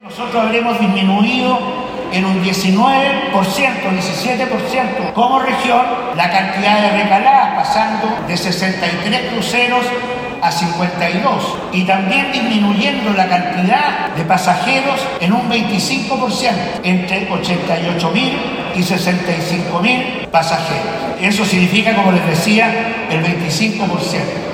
Fue en el Terminal Internacional y Centro de Vinculación Ciudad Puerto, de la capital regional, donde se reunieron en la ceremonia oficial, miembros de Empormontt, autoridades políticas y empresarios del turismo.